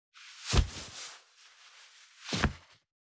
401,パーカーの音,衣服の音,衣類の音,サラッ,シャッ,スルッ,カシャッ,シュルシュル,パサッ,バサッ,フワッ,
効果音洋服関係